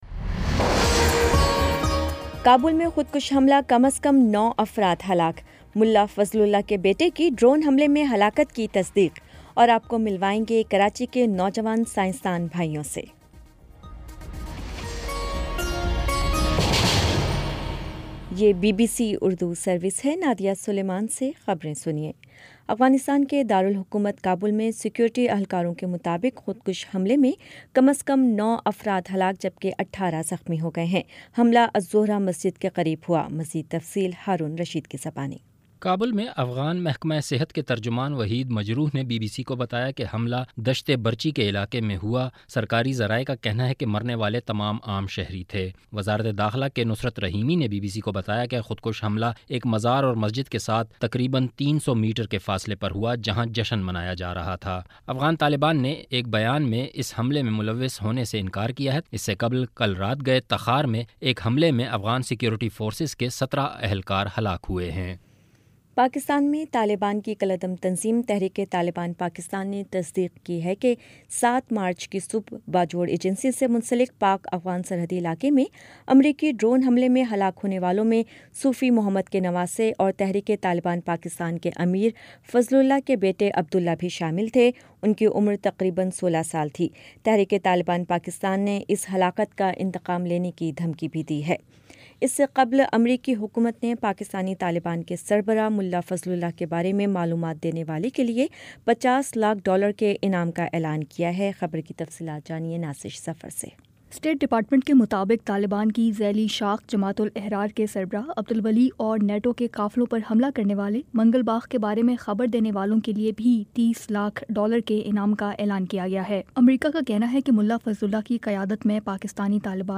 مارچ 09 : شام چھ بجے کا نیوز بُلیٹن
دس منٹ کا نیوز بُلیٹن روزانہ پاکستانی وقت کے مطابق شام 5 بجے، 6 بجے اور پھر 7 بجے۔